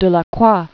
(də-lä-krwä), (Ferdinand Victor) Eugène 1798-1863.